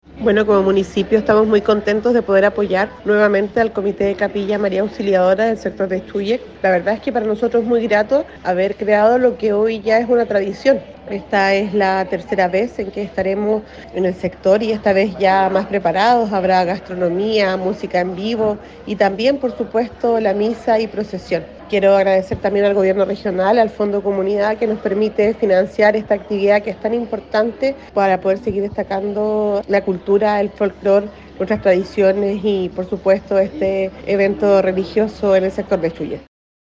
La alcaldesa curacana Javiera Yáñez indicó que esta festividad religiosa se ha transformado en los últimos tres años en uno de los eventos más importantes del verano, creando un punto de unión, encuentro, fe y devoción entre los vecinos y los turistas:
ALCALDESA-FIESTA-DE-LA-LUZ-.mp3